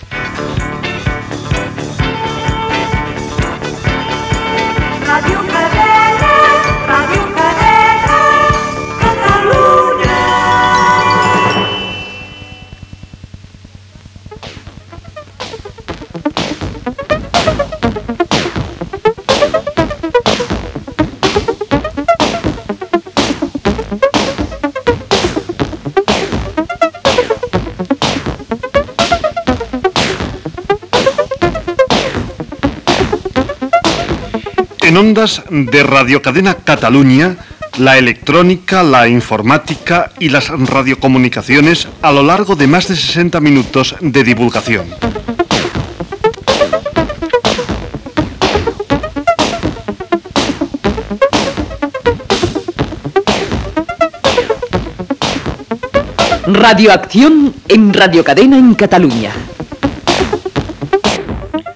Sintonia d'entrada del programa.